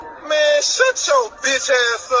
man shut yo b tch a up Meme Sound Effect
Category: Reactions Soundboard